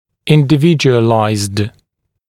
[ˌɪndɪ’vɪʤuəlaɪzd] [-djuə-][ˌинди’виджуэлайзд] [-дйуэ-]индивидуальный, созданный, разработанный для конкретного человека, пациента